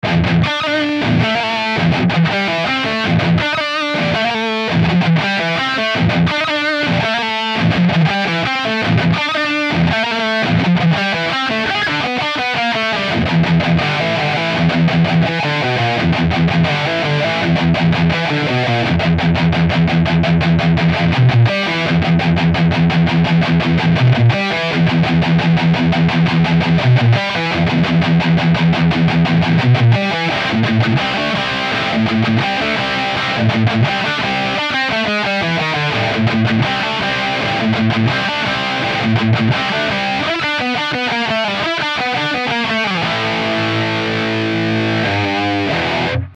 - Canal lead, Fat 0 :
Les EQ sont à midi, et le gain du lead à 2-3 sur tous les samples.
Evil Eddie_Lead 1 V0.mp3